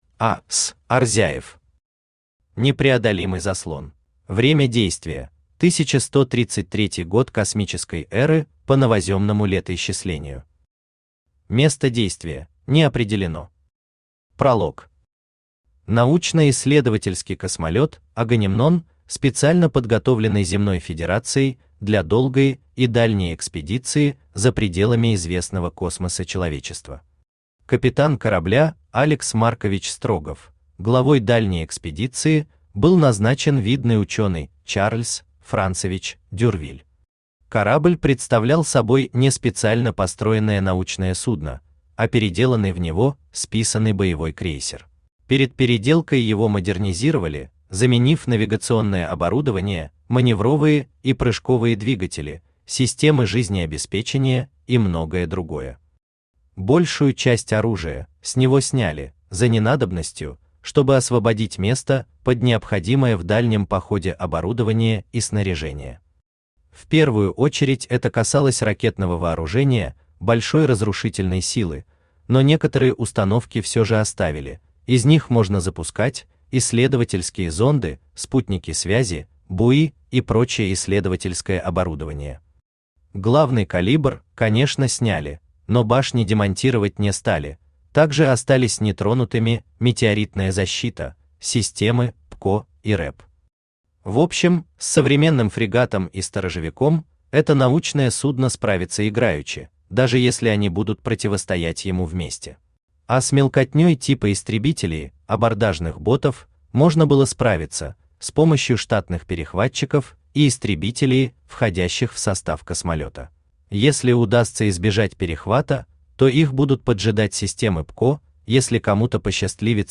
Aудиокнига Непреодолимый заслон Автор А.С. Арзяев Читает аудиокнигу Авточтец ЛитРес.